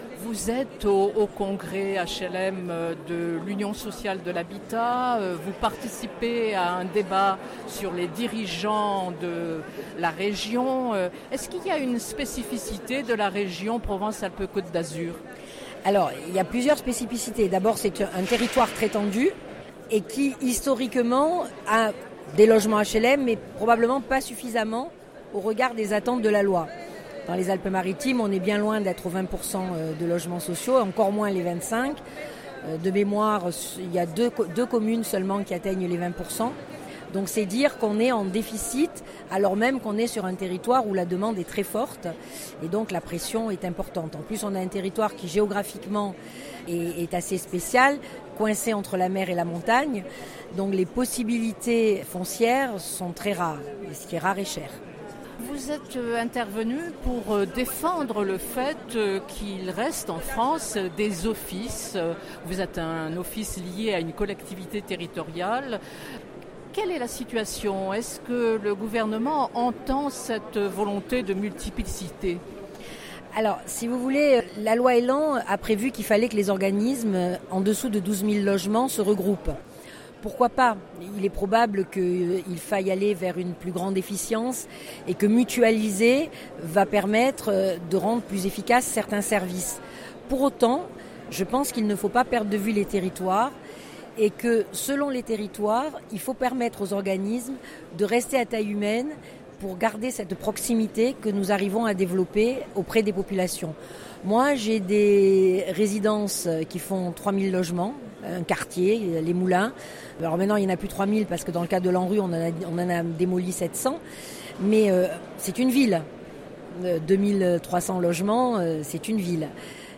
Entretien.